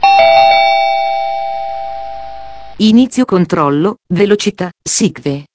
Allarmi vocali per ogni versione del TomTom
Boing = Inizio SICVE